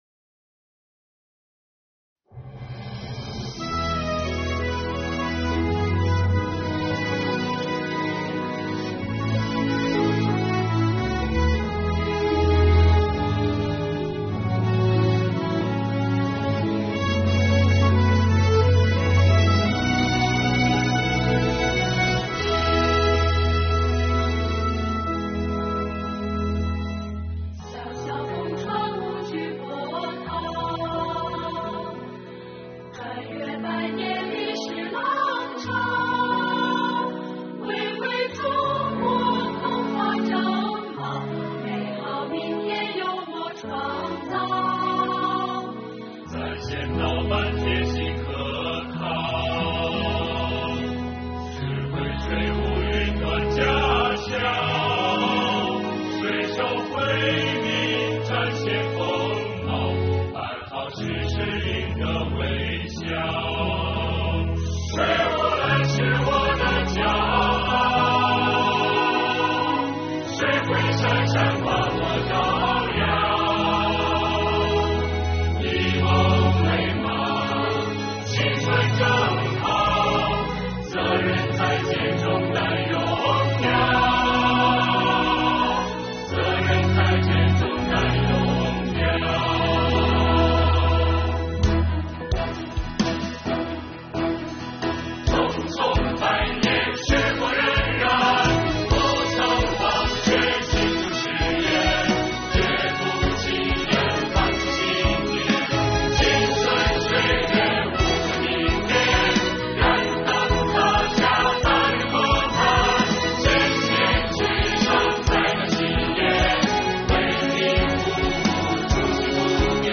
为庆祝中国共产党成立100周年，国家税务总局北京市通州区税务局的干部们通过歌声表达自己心中对党和祖国的热爱。
词：通州区税务局集体创作
曲：李罡
演唱者：通州区税务局干部代表